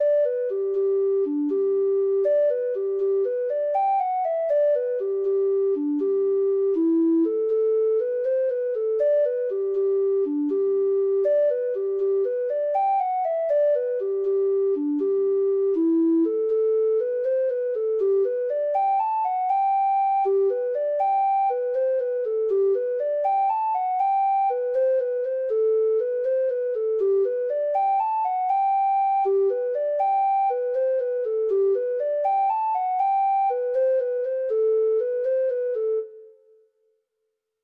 Free Sheet music for Treble Clef Instrument
Traditional Music of unknown author.
Irish Slip Jigs